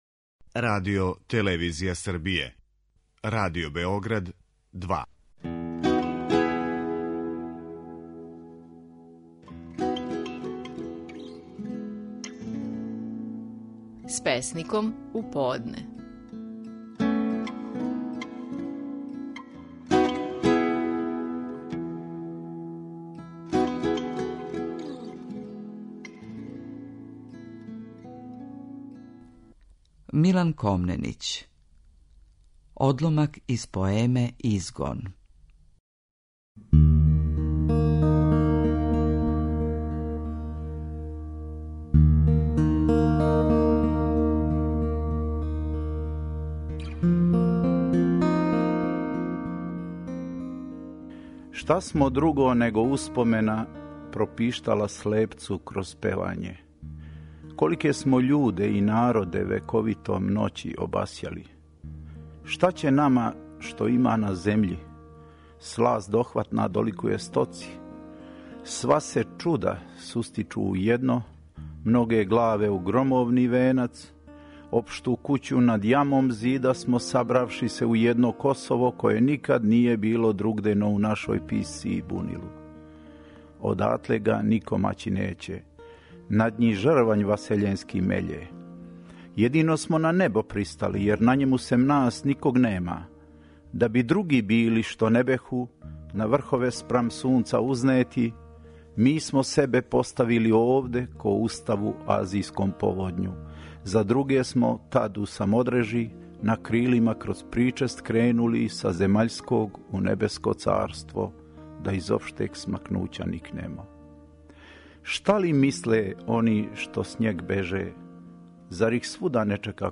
Наши најпознатији песници говоре своје стихове
Милан Комненић говори одломак из своје поеме „Изгон".